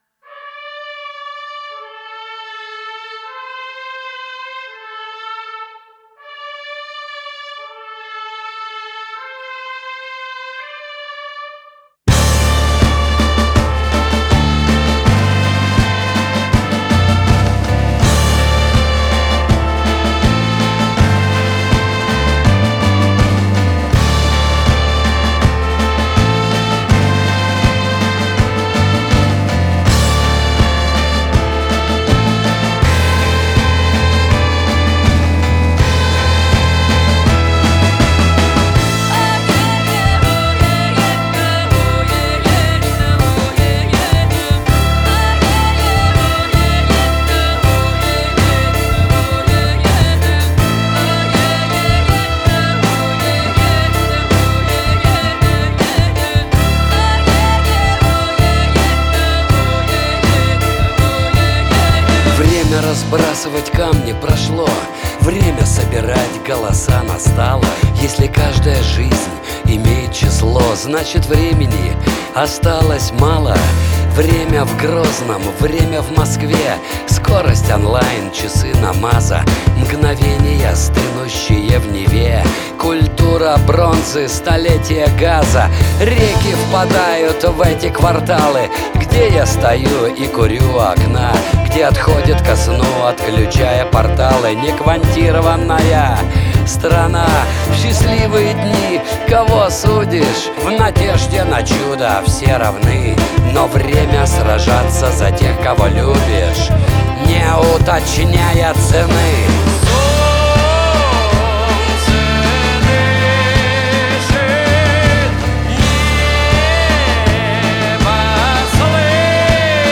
Русский Рок
Genre: Rock